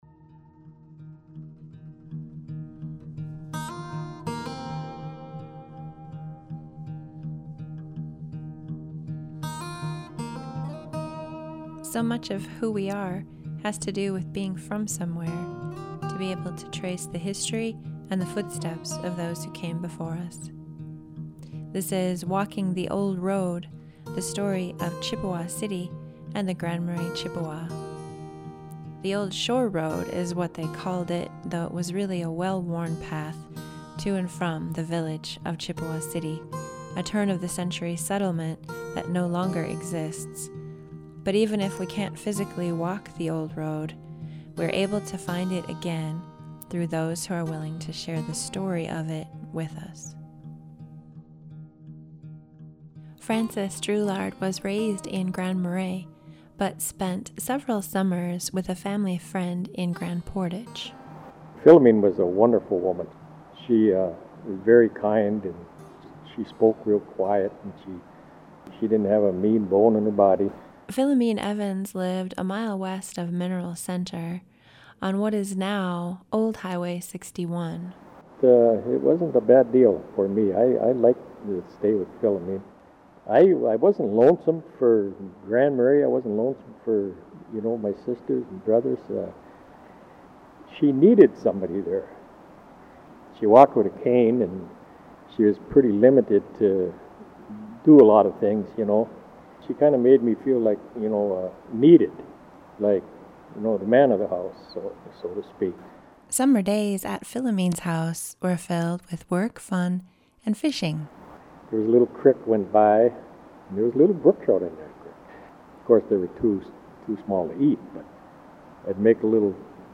Photos for this series are courtesy of the Cook County Historical Society and portions of some achieved interviews courtesy of the Grand Portage National Monument.